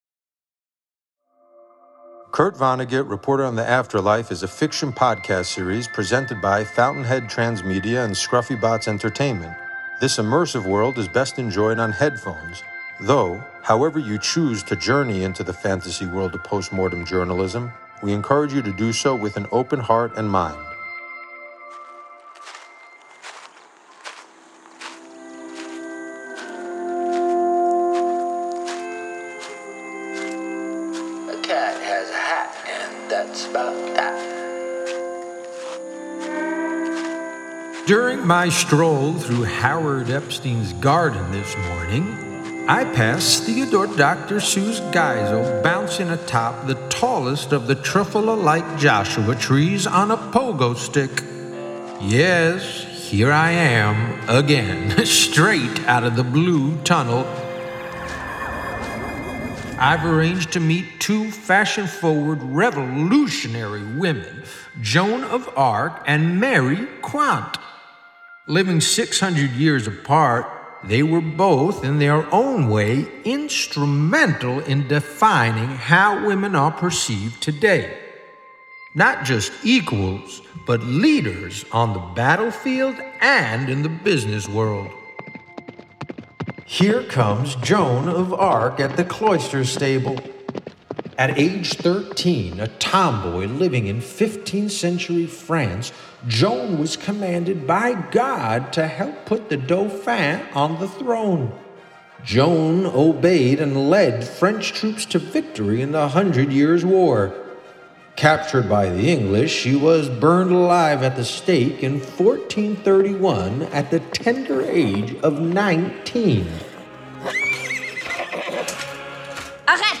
Kurt Vonnegut interviews Joan of Arc and Mary Quant. Living 600 years apart, they were both in their own way instrumental in defining how women are perceived today.